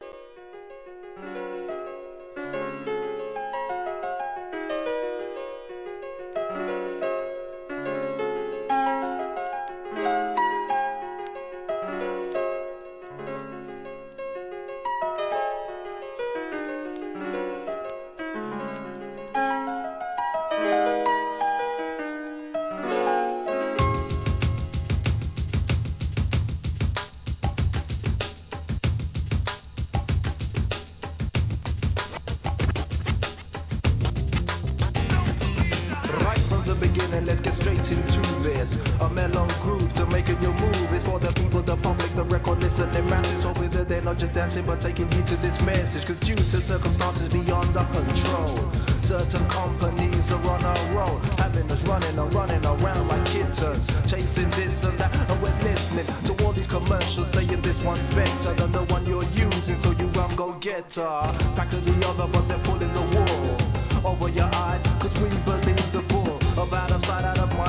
HIP HOP/R&B
JAZZやHIP HOP, SOUL等をブレンドさせたACID JAZZ作品!!